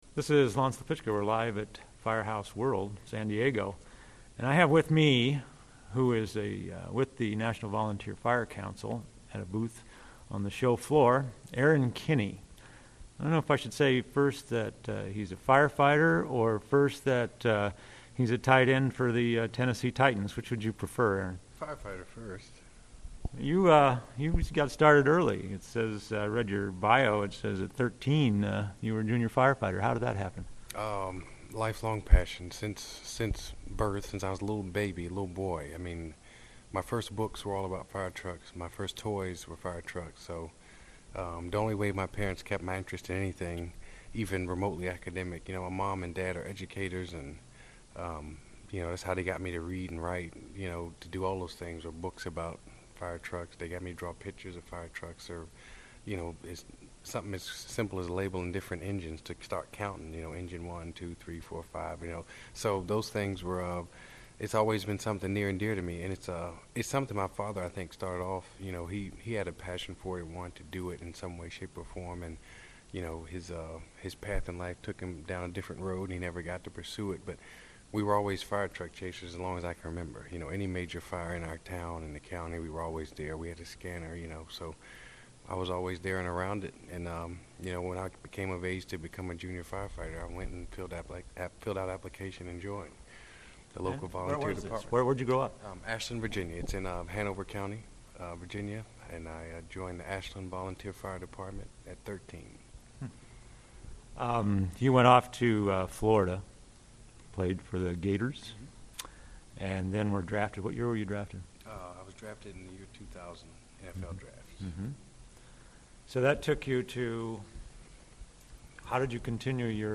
Interview with Erron Kinney at Firehouse World